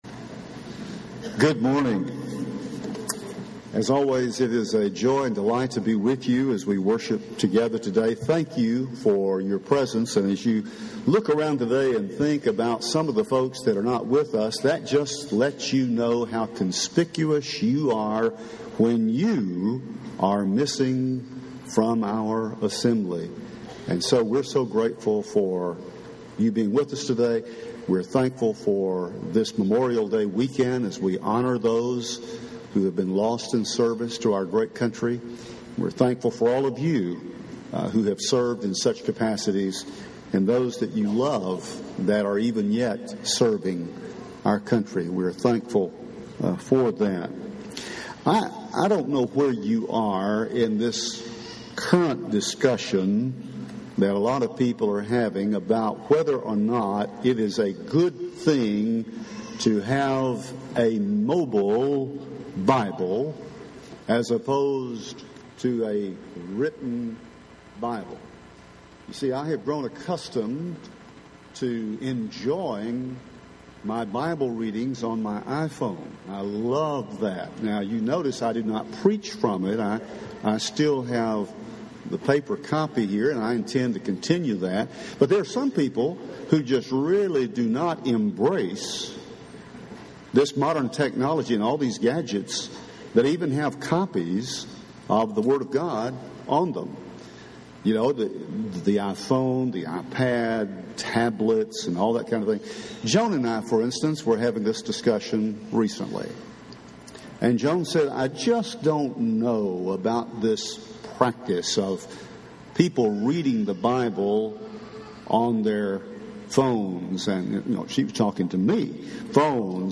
– Henderson, TN Church of Christ